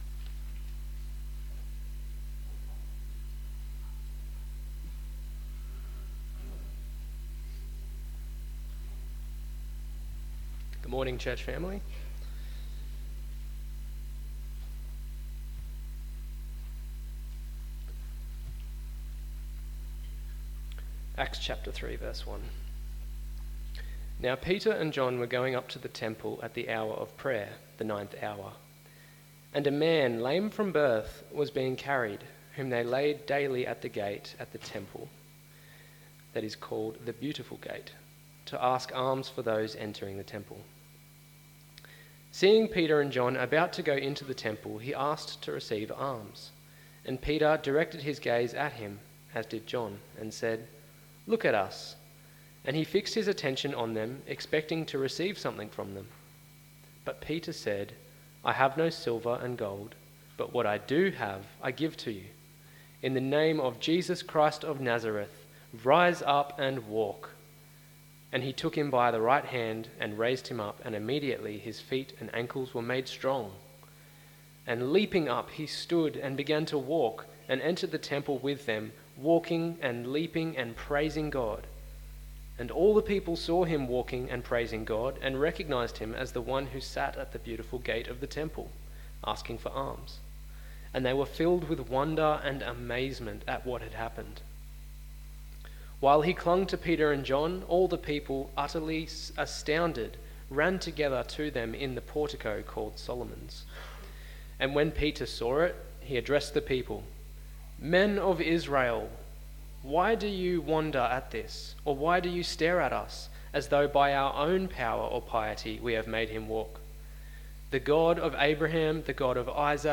Jesus (Still) Saves AM Service
sermon podcasts